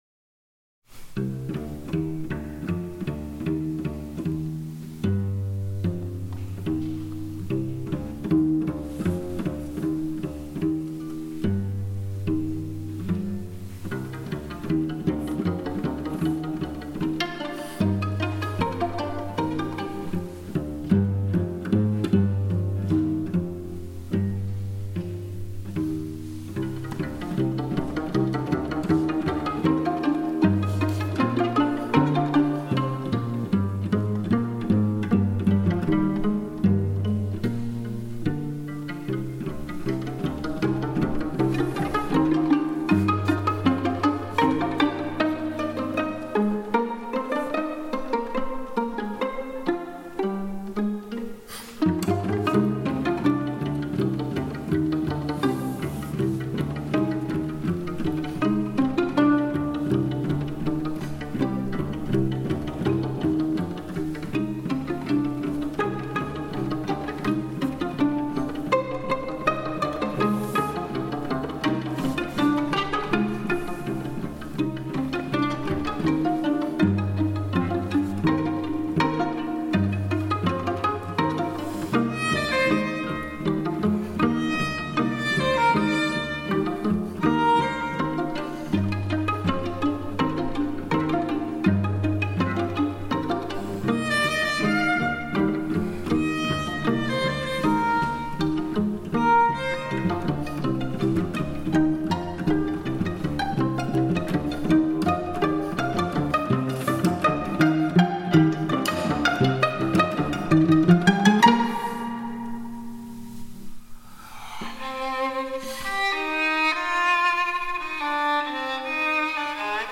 4. Genre: New classical
As a student of composition, I wanted to test this theory to the extreme: Go pizz on all the instruments in a string quartet composition for the entire work.
Recorded 18 December 2012, Queens College New Music Group concert, Queens College.